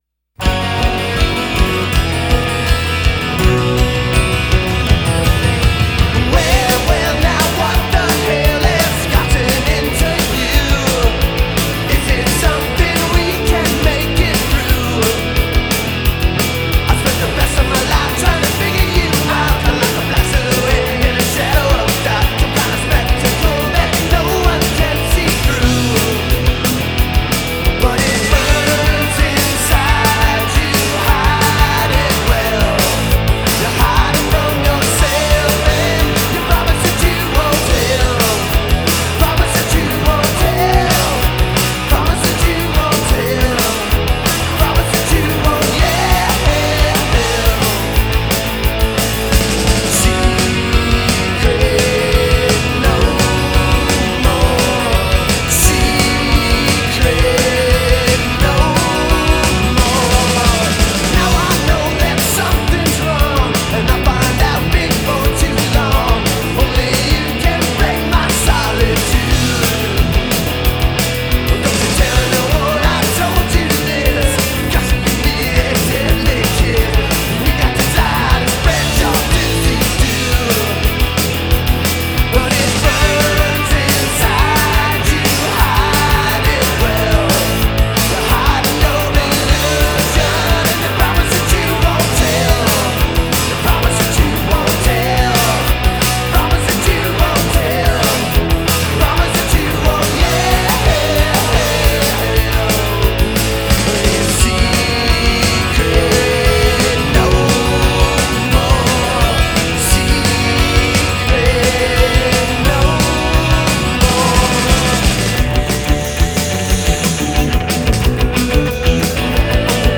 at Paisley Park Studio B